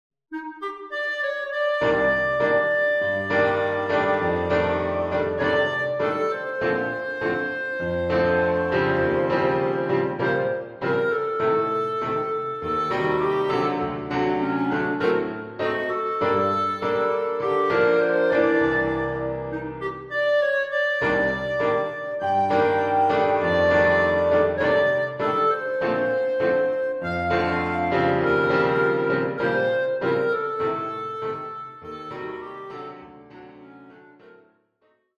(Bossa)